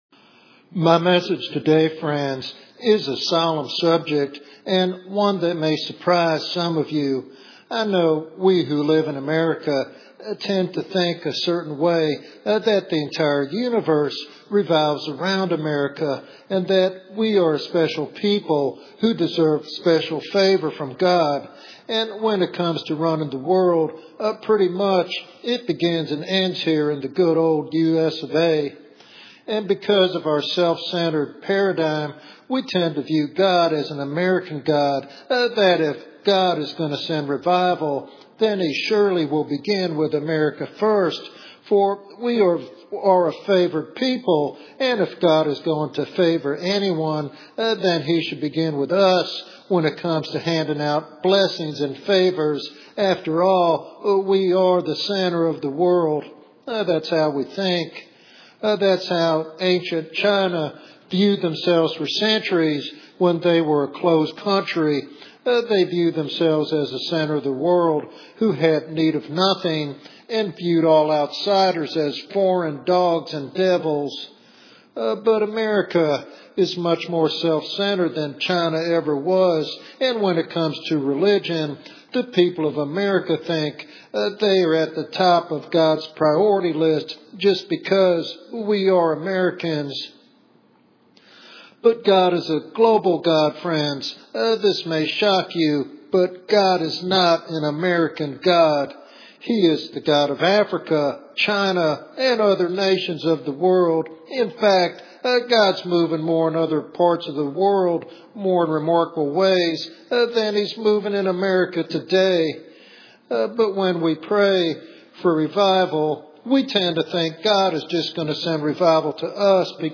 He emphasizes God's sovereignty over all nations and the urgent need for humility and prayer. This sermon serves as a solemn wake-up call to believers about the spiritual state of America.